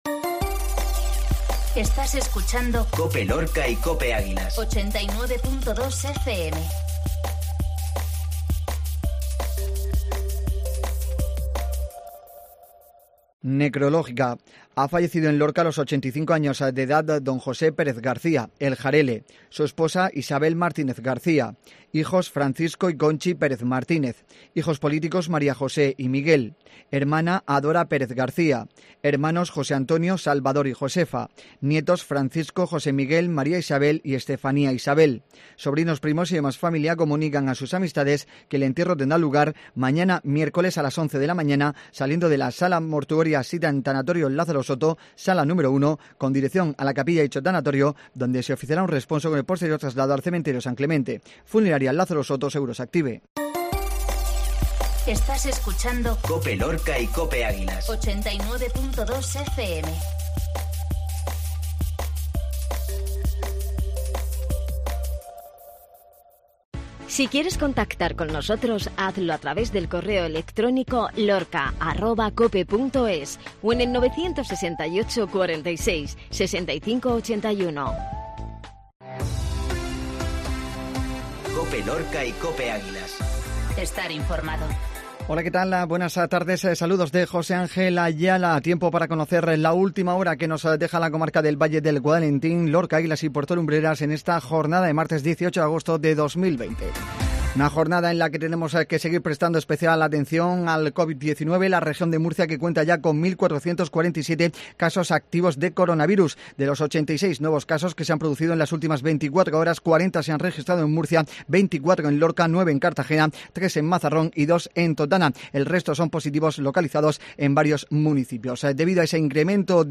INFORMATIVO MEDIODÍA COPE LORCA